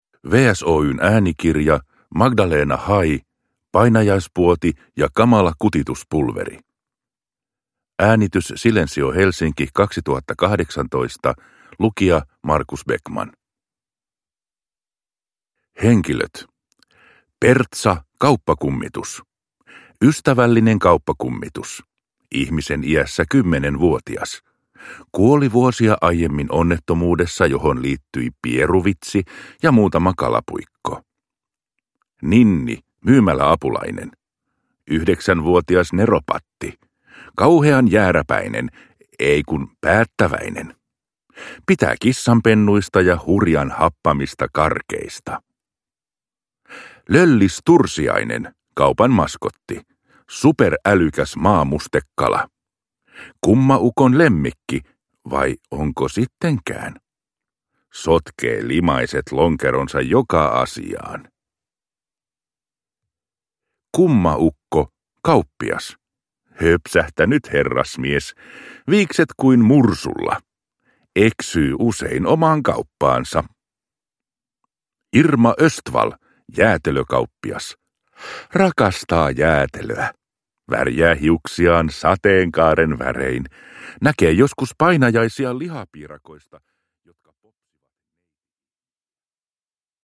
Painajaispuoti ja kamala kutituspulveri – Ljudbok